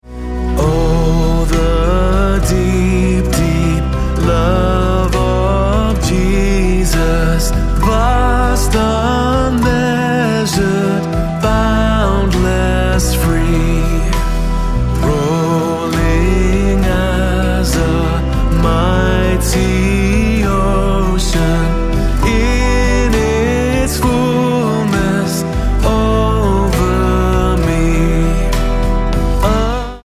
Em